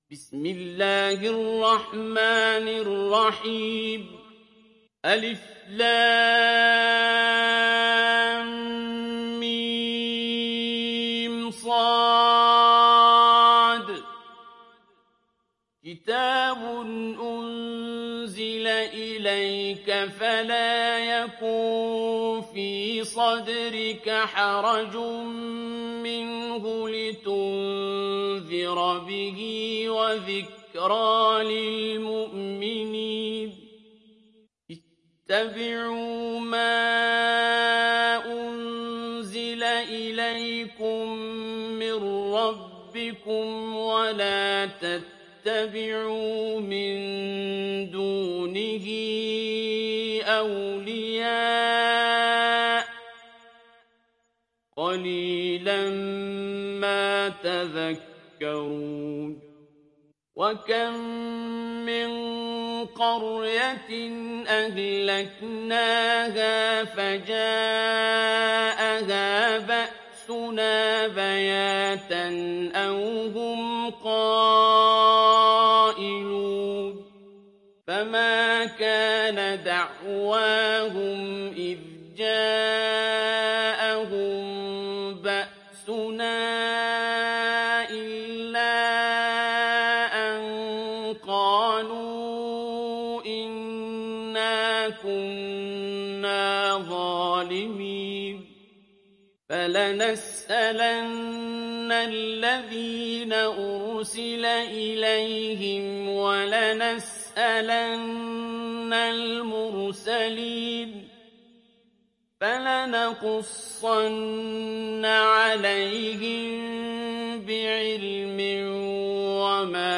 تحميل سورة الأعراف mp3 بصوت عبد الباسط عبد الصمد برواية حفص عن عاصم, تحميل استماع القرآن الكريم على الجوال mp3 كاملا بروابط مباشرة وسريعة